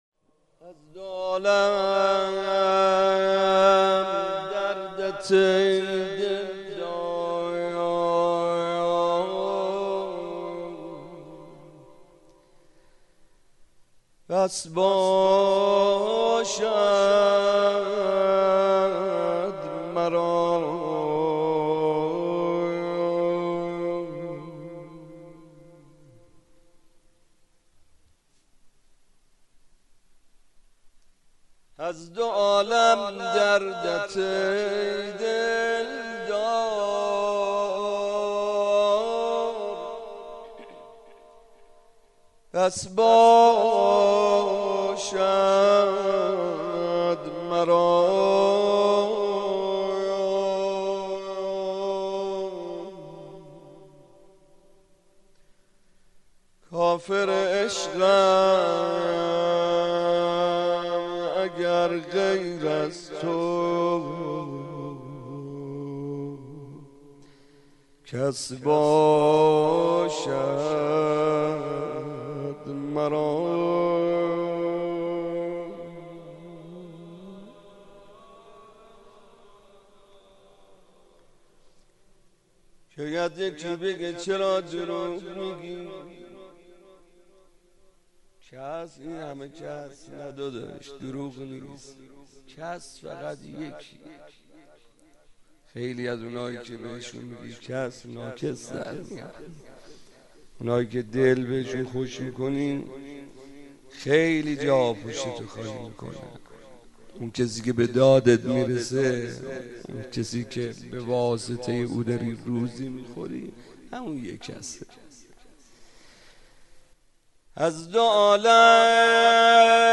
01.monajat.mp3